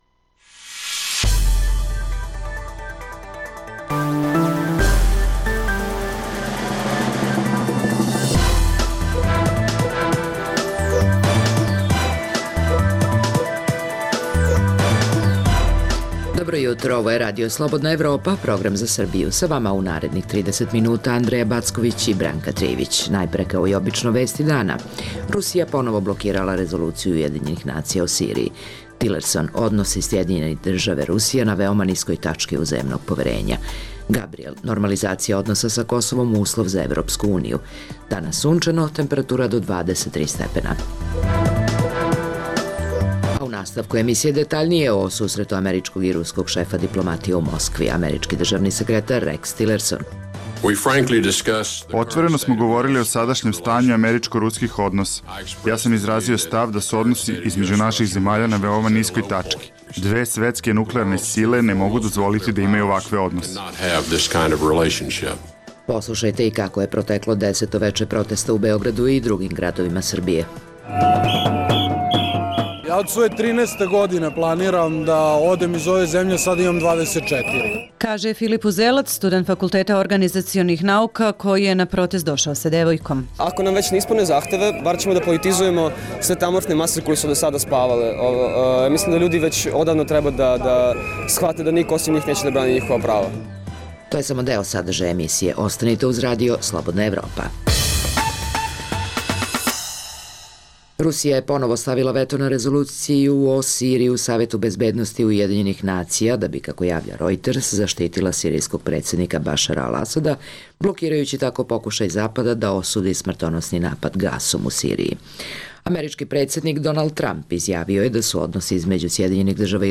Emisija namenjena slušaocima u Srbiji koja sadrži lokalne, regionalne i vesti iz sveta te tematske priloge o aktuelnim dešavanjima priče iz svakodnevnog života.